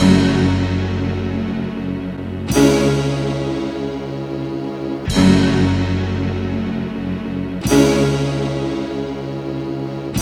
Ridin_ Dubs - Cinematic Strings.wav